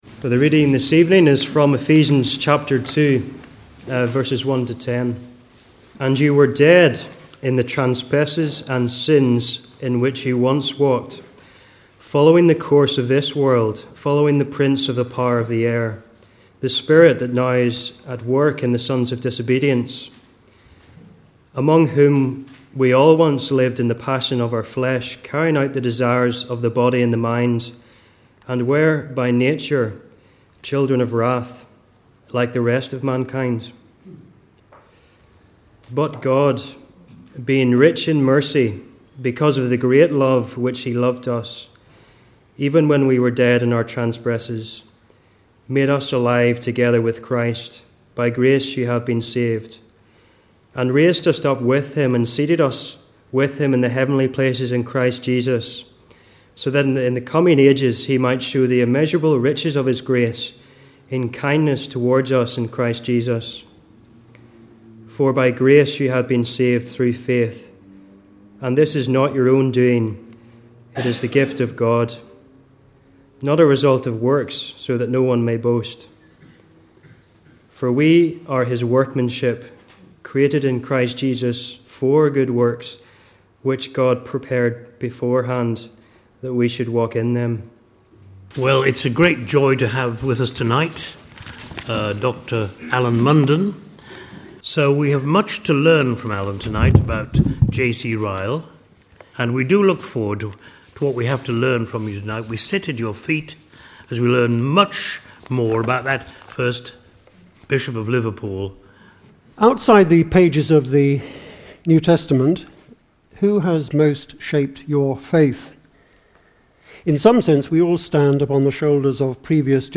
2012 Autumn Lectures